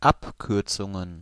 Ääntäminen
Ääntäminen Tuntematon aksentti: IPA: /ˈapʔ.ˌkʏːʁ.ʦʊ.ŋən/ Haettu sana löytyi näillä lähdekielillä: saksa Käännöksiä ei löytynyt valitulle kohdekielelle.